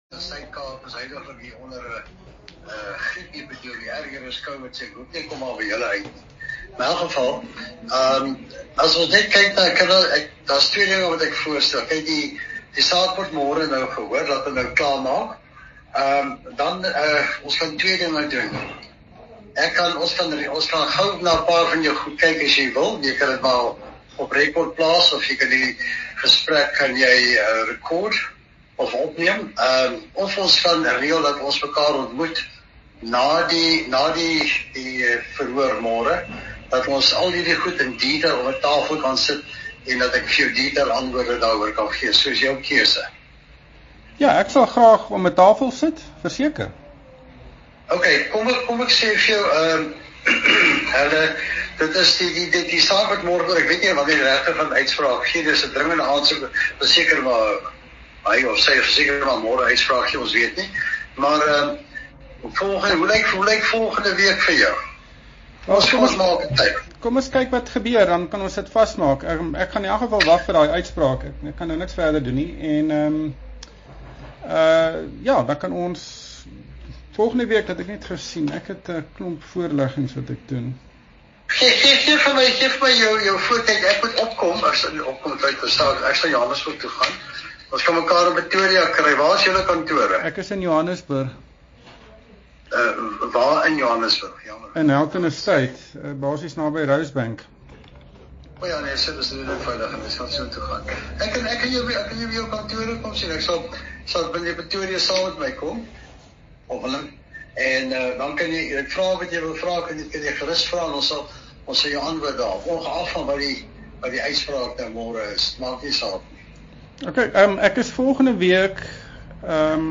Latest Special ReportsThese podcasts range from an in-depth interviews with business leaders, as well as the analysis of the news of the day, comprehensive analyses of news events to inspirational interviews with business leaders.